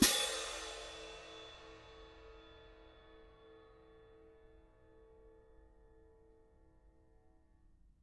cymbal-crash1_mp_rr2.wav